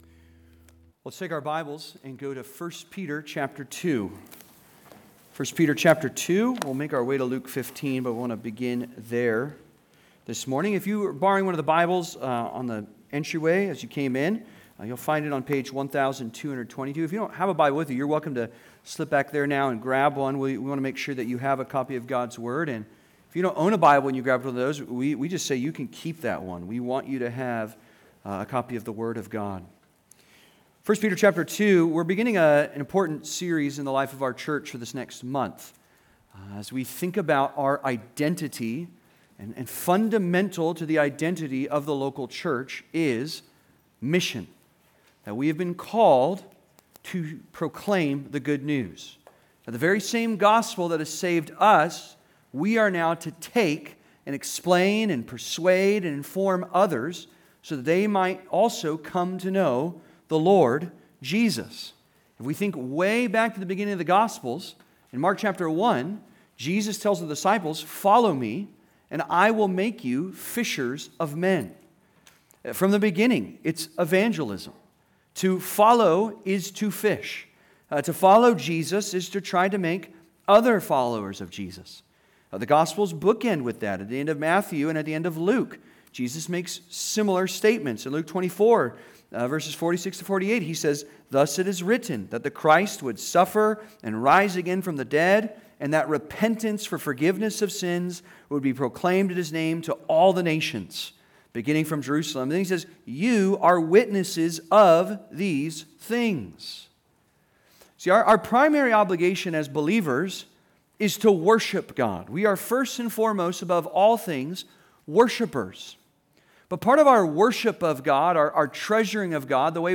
This Man Receives Sinners (Sermon) - Compass Bible Church Long Beach